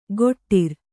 ♪ goṭṭir